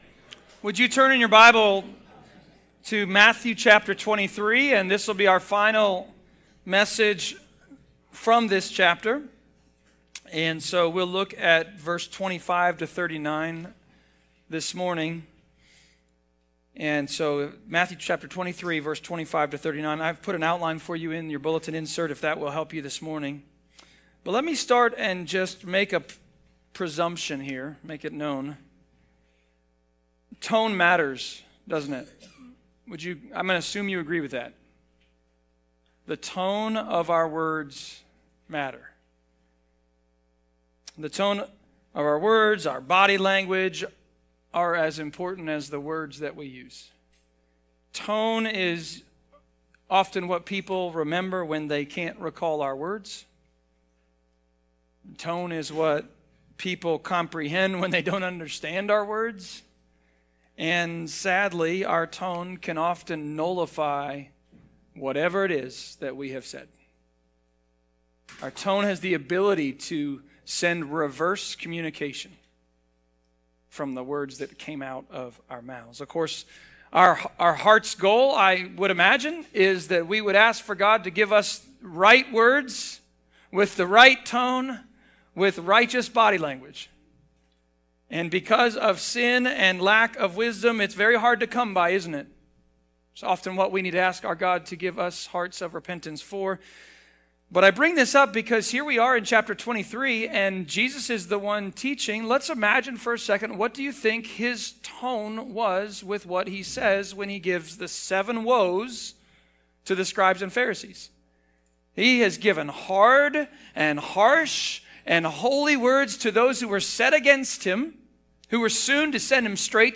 Matthew Passage: Matthew 23.25-39 Service Type: Sermons « “Woe-zers